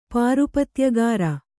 ♪ pārupatyagāra